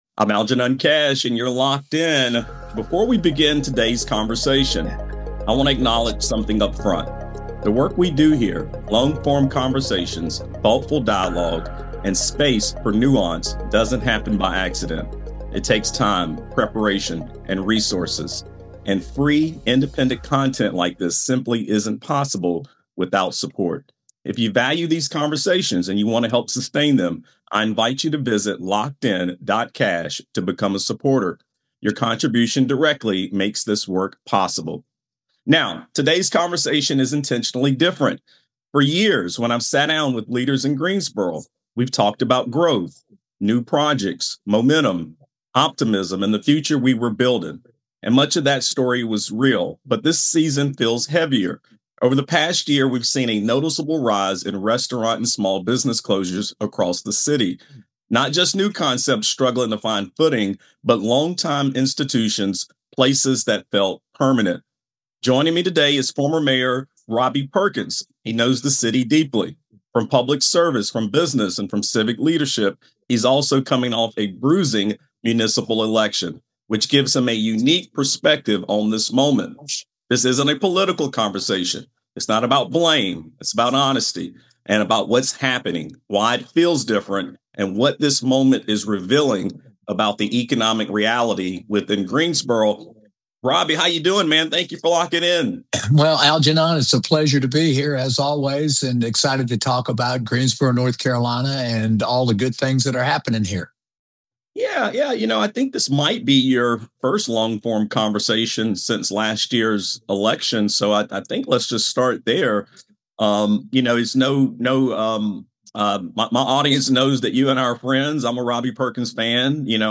A candid conversation with former Mayor Robbie Perkins on Greensboro restaurant closures, downtown decline, and the economic strain reshaping the city.